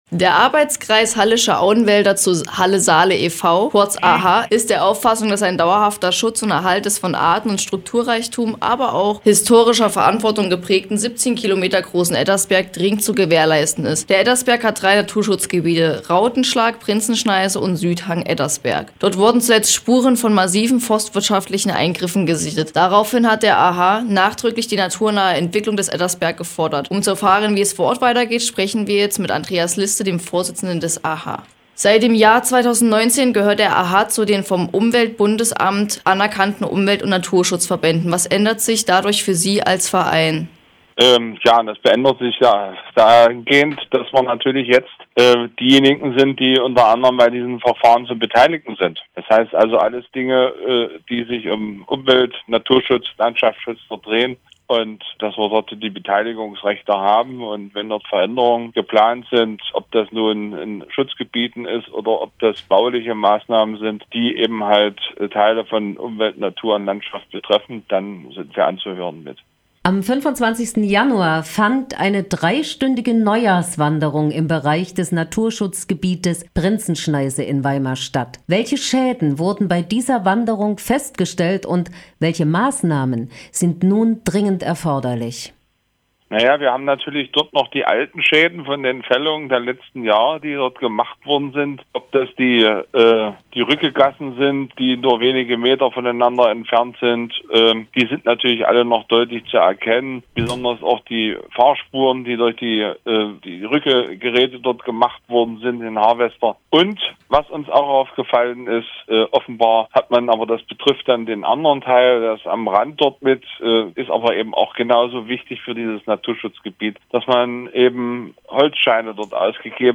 Forderung naturnahe Entwicklung Ettersberg – Interview